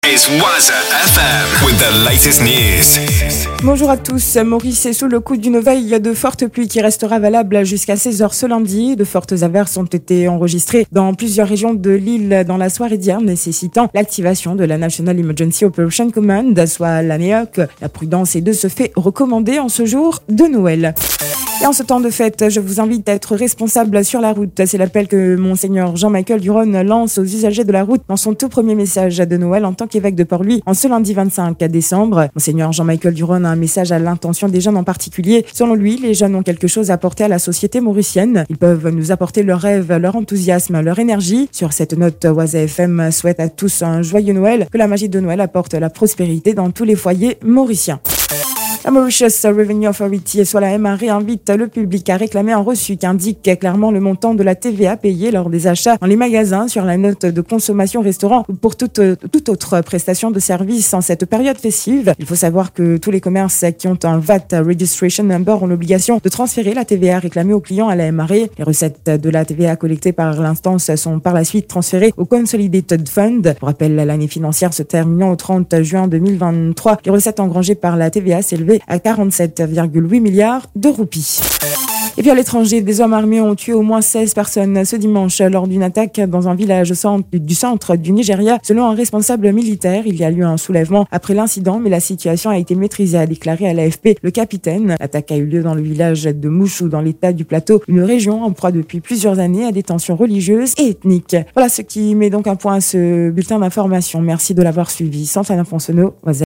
NEWS 10h - 25.12.23